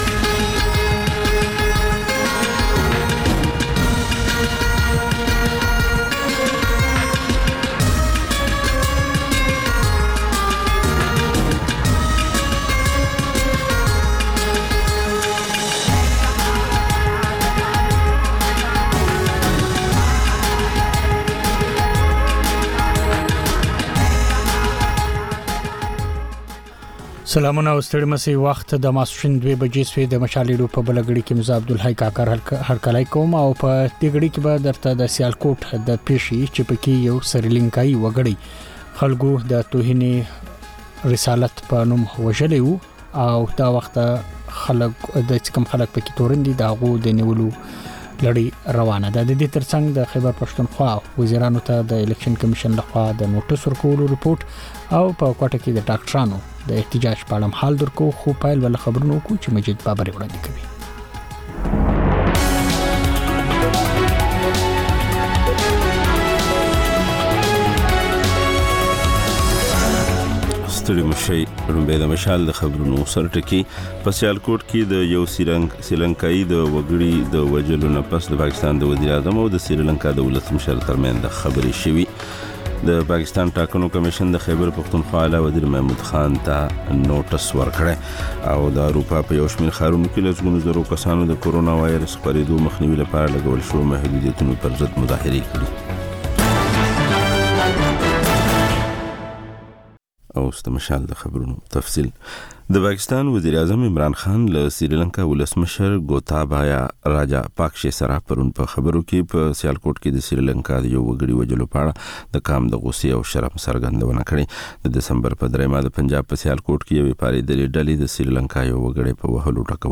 د مشال راډیو دویمه ماسپښینۍ خپرونه. په دې خپرونه کې لومړی خبرونه او بیا ځانګړې خپرونه خپرېږي.